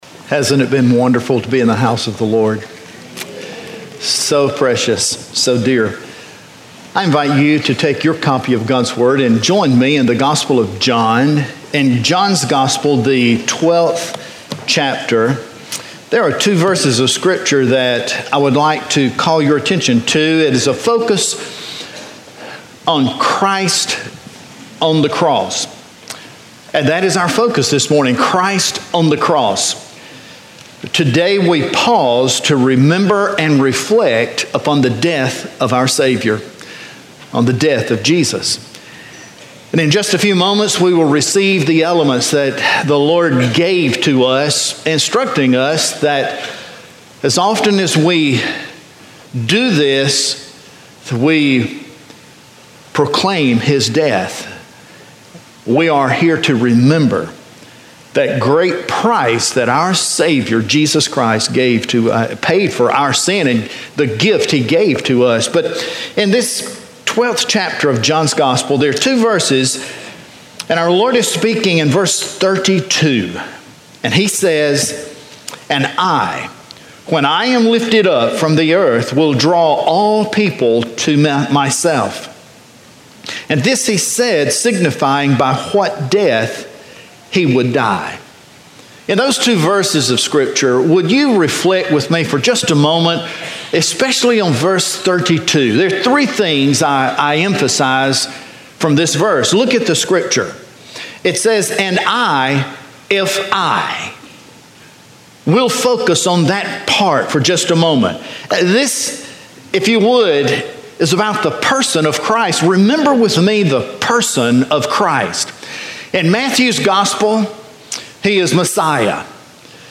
4-30-17 Sermon FBCLB.mp3